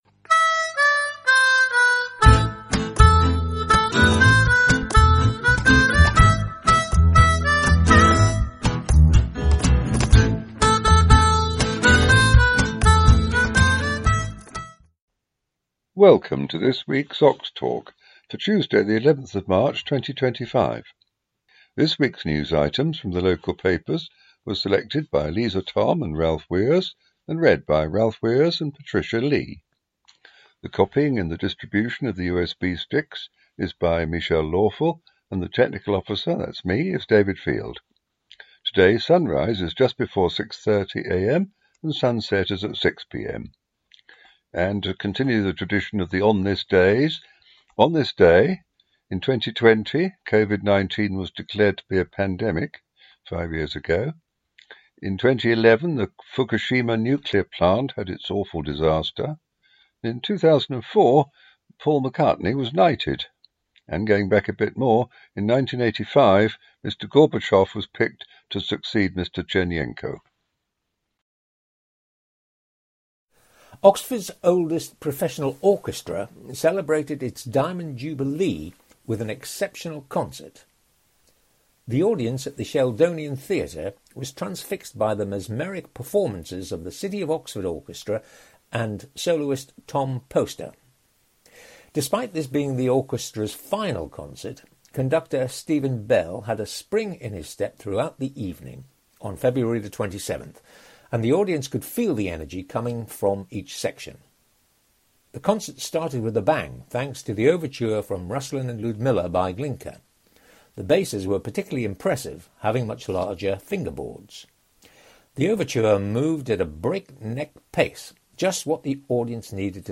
11th March 2025 edition - Oxtalk - Talking newspapers for blind and visually impaired people in Oxford & district